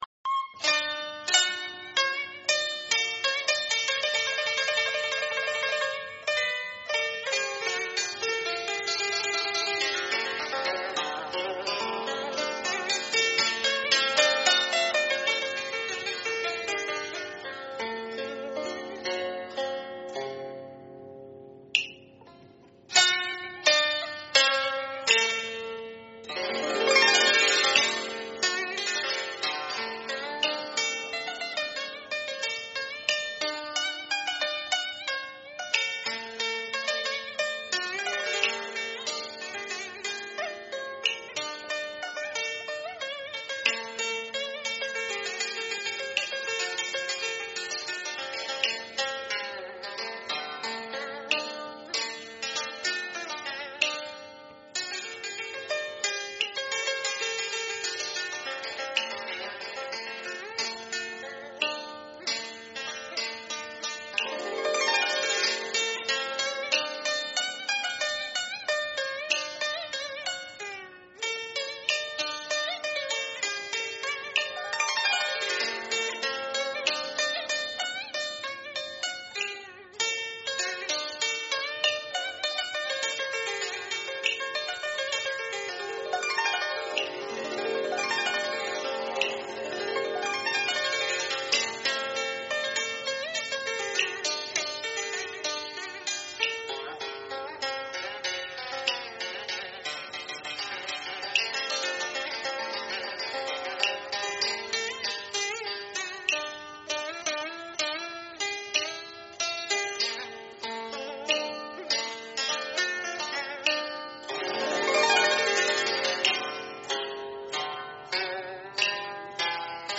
= народная … Вьетнамская … =
5-Vetnamskaya-narodnaya-muzyka-Suong-Chieu_audio-paladin.ru_.mp3